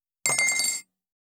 242,テーブル等に物を置く,食器,グラス,コップ,工具,小物,雑貨,コトン,トン,ゴト,ポン,ガシャン,ドスン,ストン,カチ,タン,バタン,スッ,サッ,コン,ペタ,パタ,チョン,コス,カラン,ドン,チャリン,
コップ効果音厨房/台所/レストラン/kitchen物を置く食器